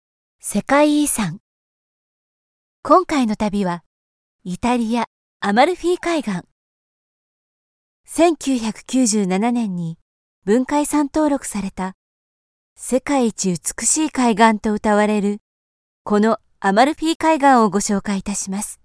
Commercial, Versatile, Reliable, Corporate, Young
Audio guide